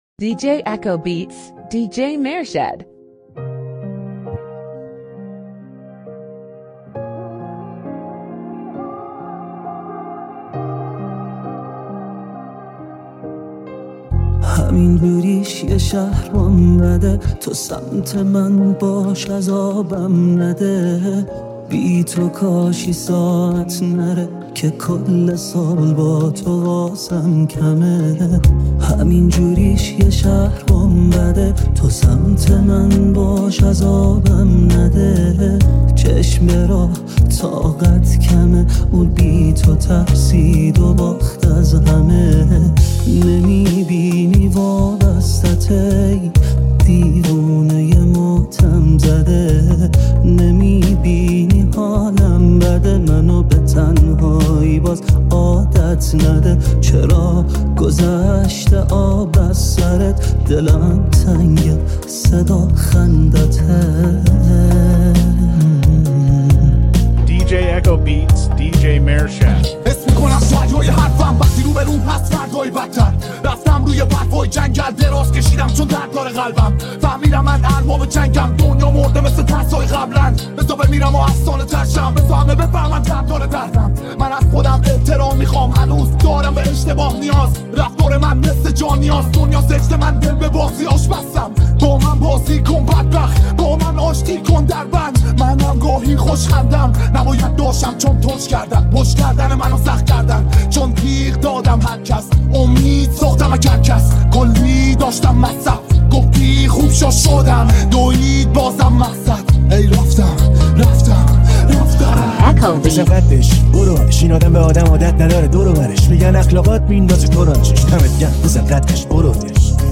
رمیکس غمگین دپ